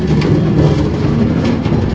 minecraft / sounds / minecart / base.ogg